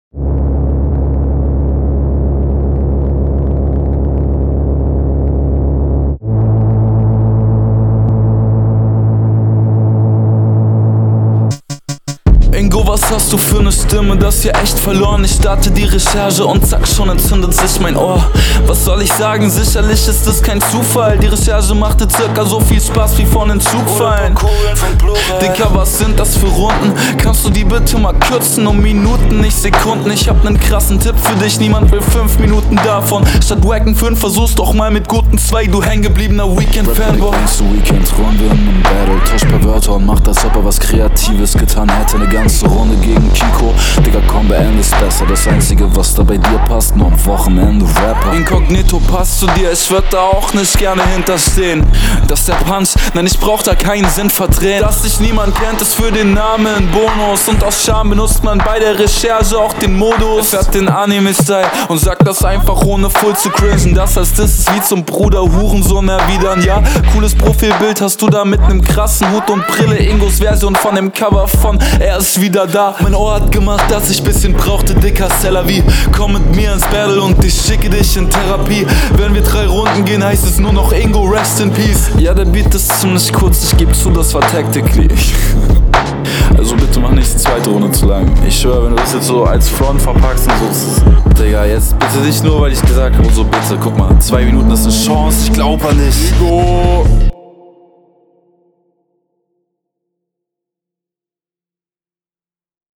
Irgendwie mag ich das sehr aber ist an manchen stellen zu holprig geflowt um komplett …